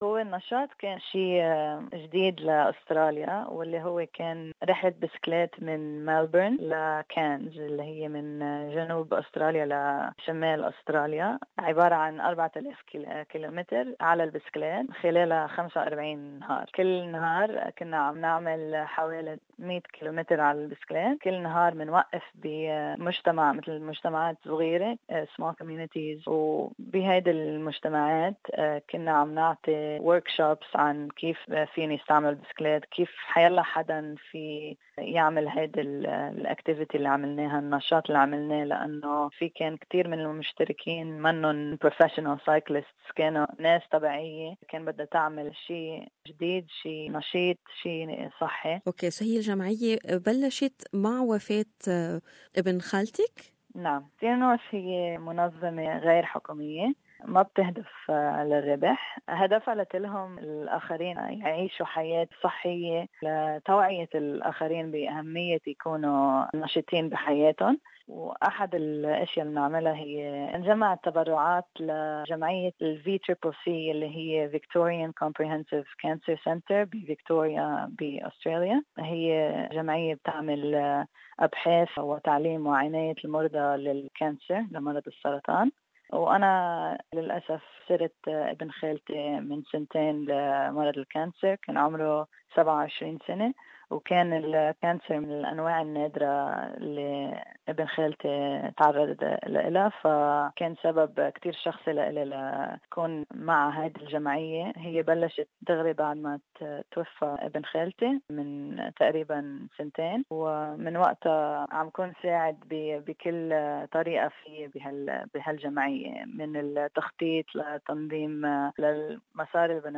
جالوا في أرجاء أستراليا لجمع التبرعات من أجل مكافحة مرض السرطان. تعرفوا الى المزيد في هذا التقرير